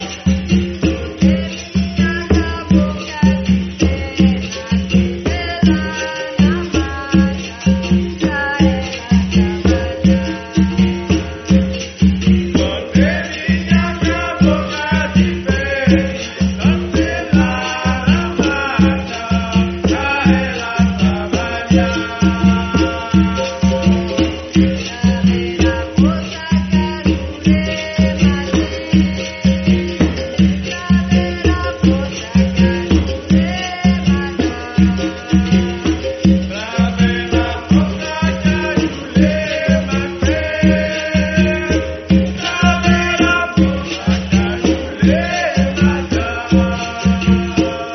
chants de capoeira
Le berimbau, instrument emblématique en forme d’arc, guide le rythme. Autour de lui, les pandeiros, atabaques et agogôs complètent l’ensemble.
Elle se déroule dans une roda, un cercle humain où les participants chantent, frappent dans les mains, et créent une atmosphère presque rituelle.